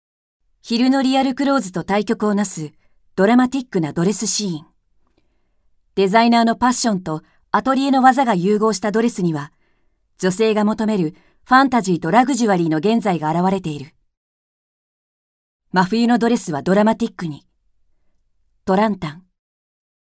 Japanese female voices